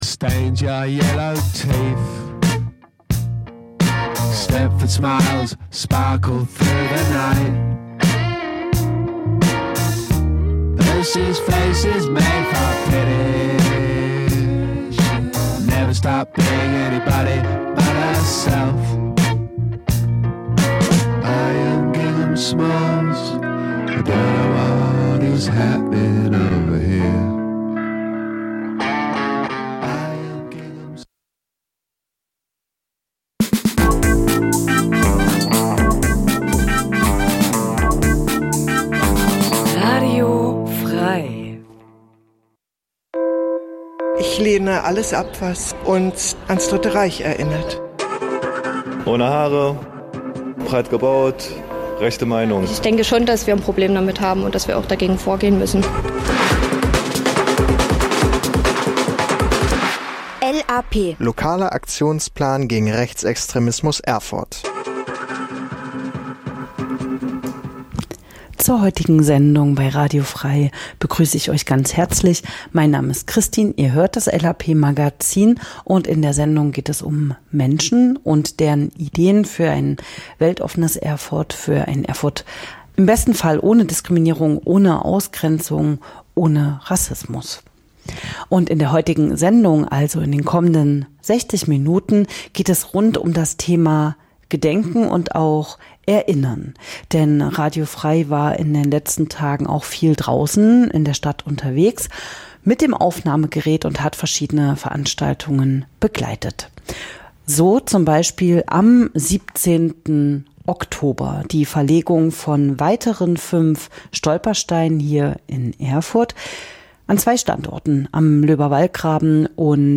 Themen der Sendung: Stolpersteine Am 17.10.2025 wurden 5 neue Stolpersteine in der Werner-Seelenbinder-Stra�e 3 und am L�berwallgraben 3 gesetzt. Radio F.R.E.I war vor Ort am L�berwallgraben und hat ein paar Eindr�cke und Stimmen gesammelt.